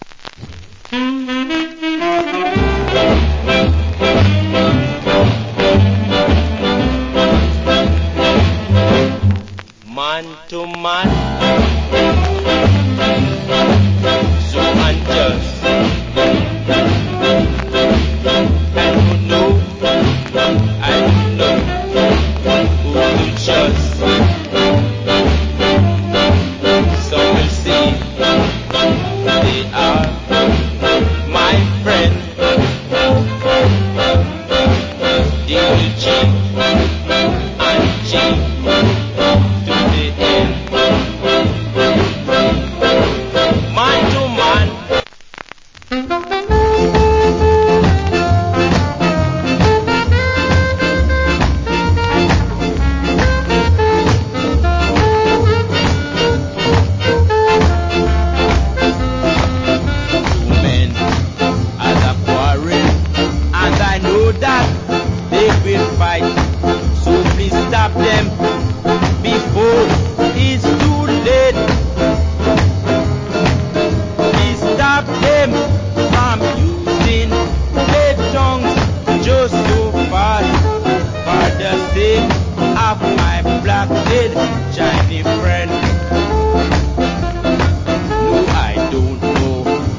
Wicked JA R&B Vocal.